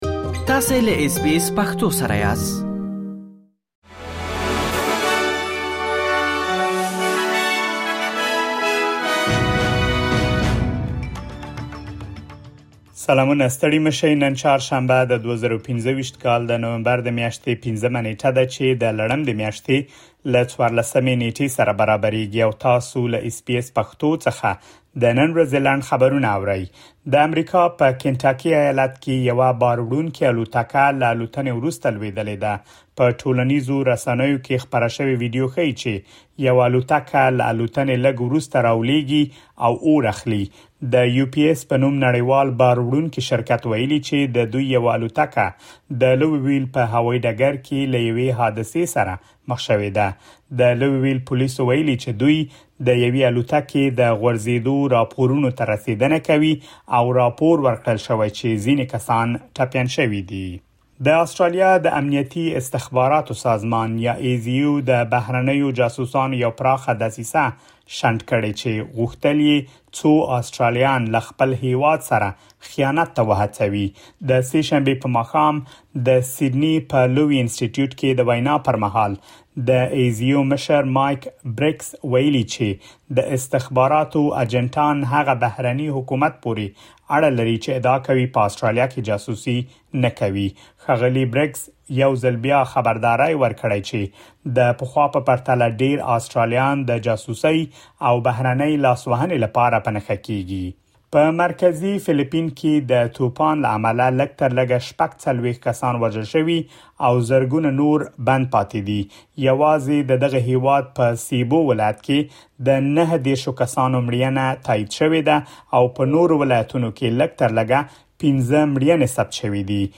د اس بي اس پښتو د نن ورځې لنډ خبرونه |۵ نومبر ۲۰۲۵